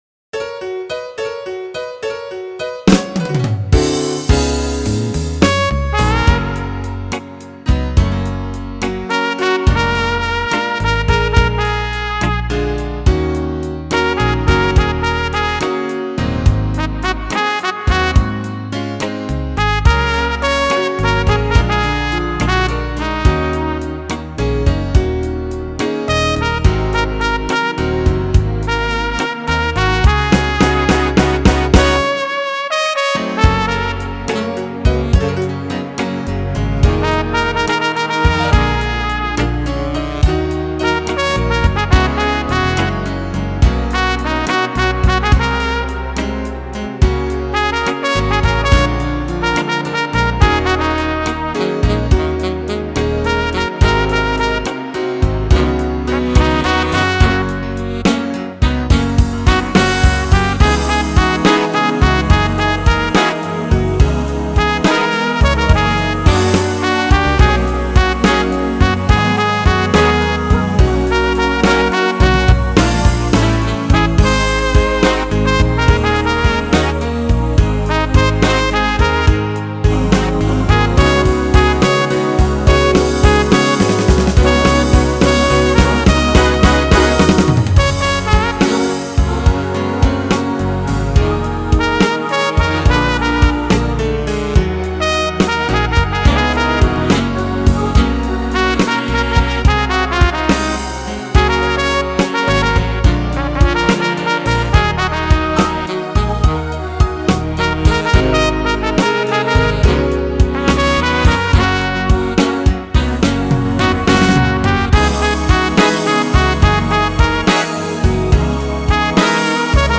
thkoxfwae5  Download Instrumental
I put myself in a New Orleans speakeasy
trumpet
in a sultry gospel style that is pure genius